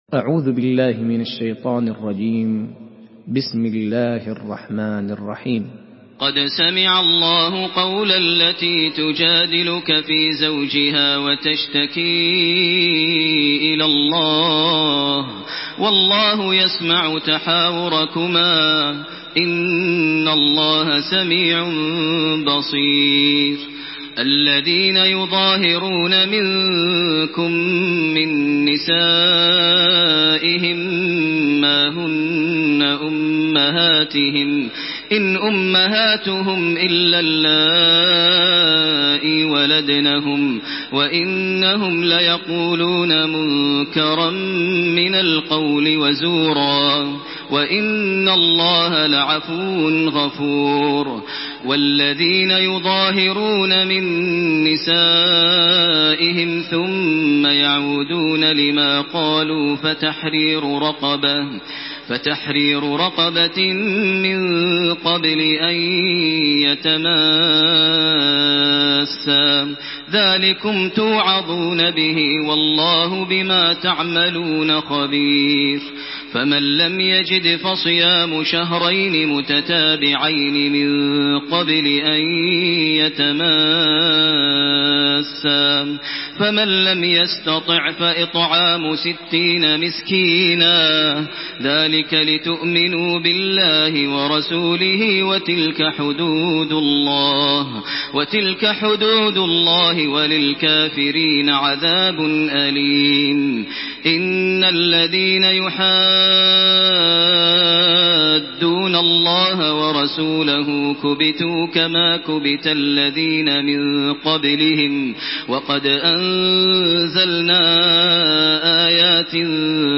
سورة المجادلة MP3 بصوت تراويح الحرم المكي 1428 برواية حفص
مرتل